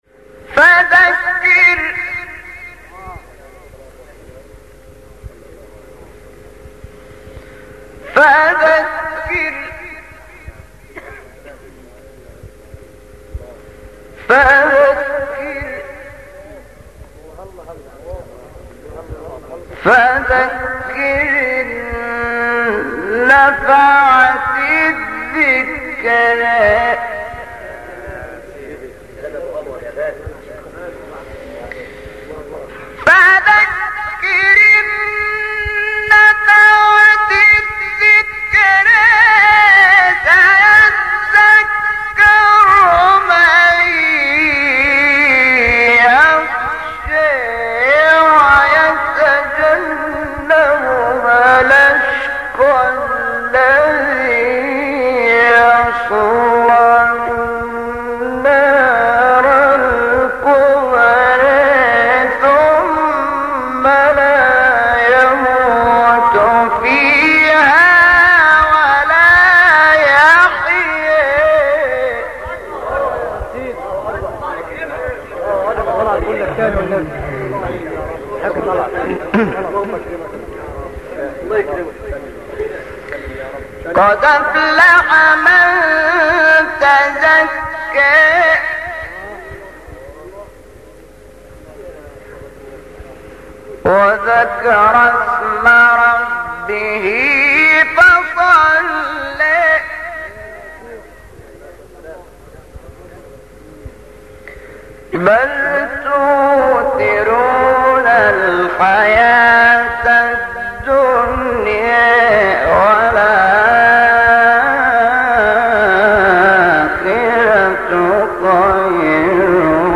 بیات استاد شحات انور | نغمات قرآن | دانلود تلاوت قرآن